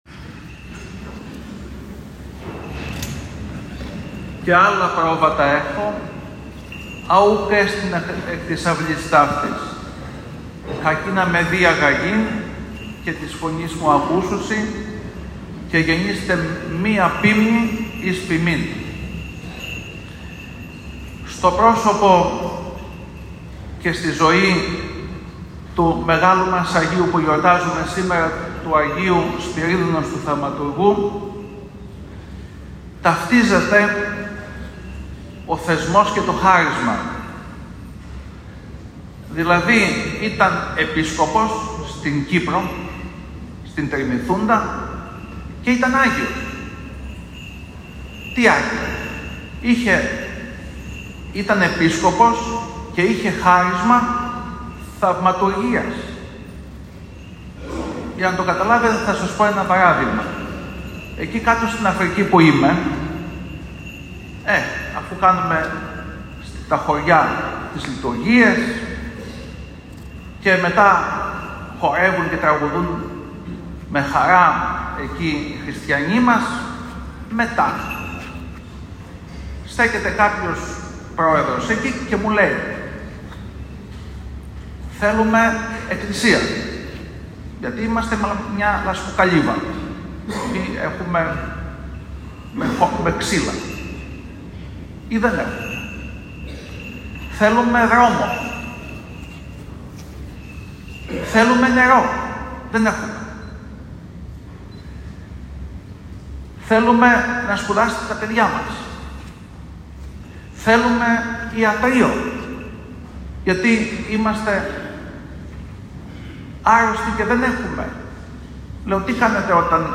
Συνεχίζονται οι λατρευτικές εκδηλώσεις για την εορτή του Αγίου Σπυρίδωνος στον ομώνυμο Ιερό Ναό στην Τριανδρία Θεσσαλονίκης.
Τον Θείο Λόγο κήρυξε ο Θεοφιλέστατος Επίσκοπος κ. Χρυσόστομος.